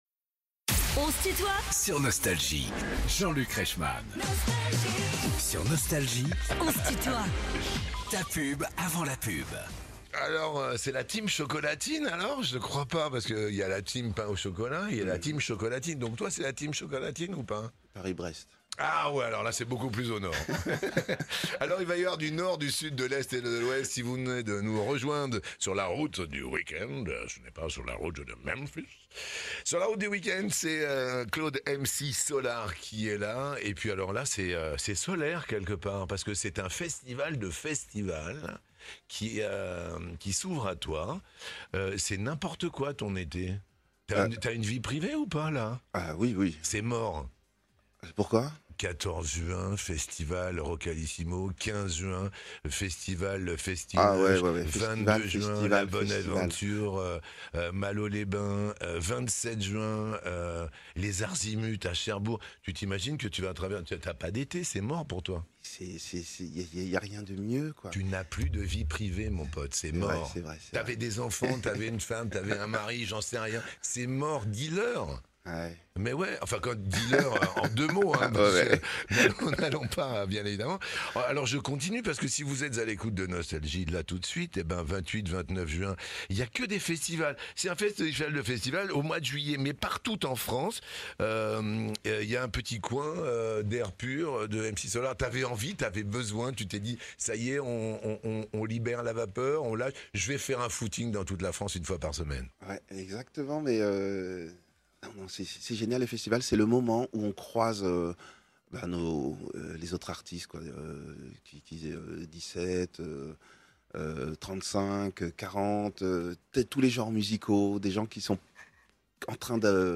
MC Solaar est l'invité de "On se tutoie ?..." avec Jean-Luc Reichmann (partie 2) ~ Les interviews Podcast